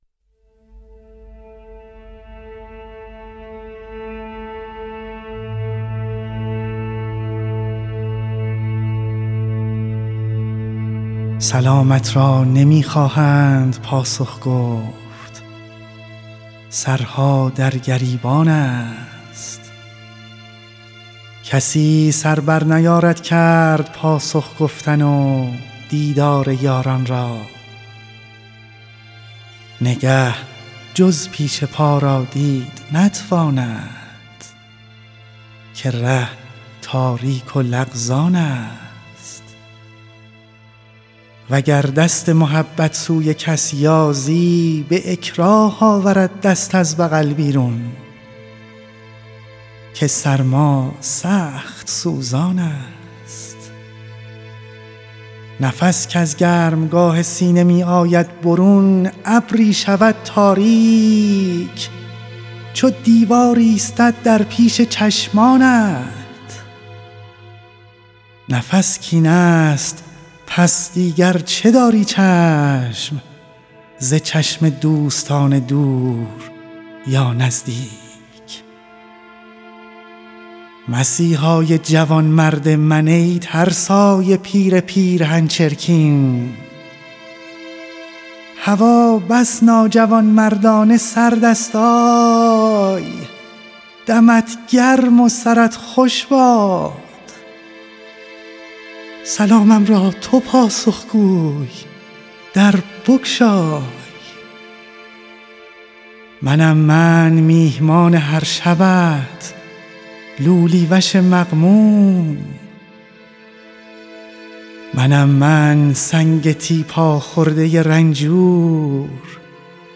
به شکل ارکسترال آهنگسازی کرده